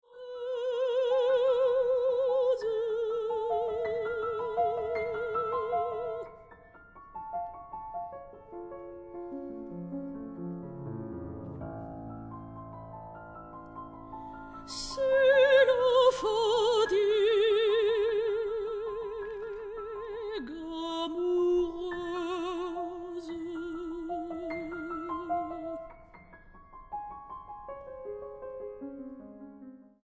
para voz y piano.